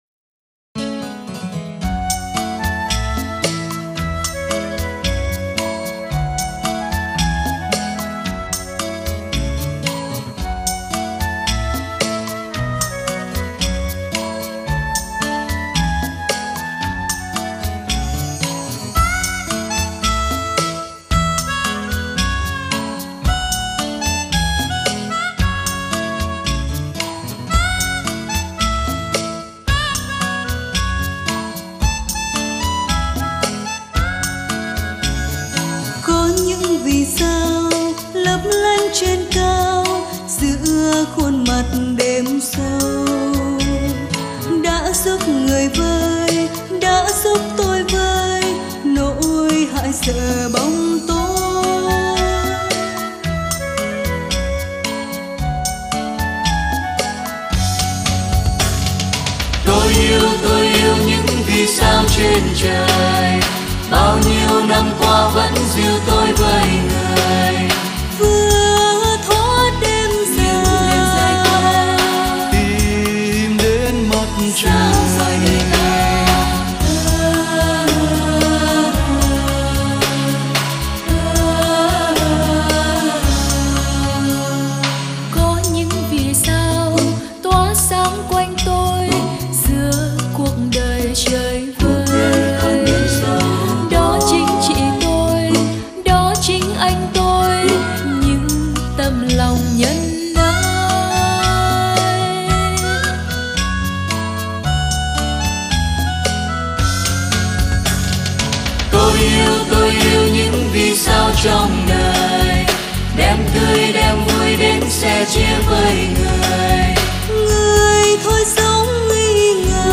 * Thể loại: Ngợi ca Thiên Chúa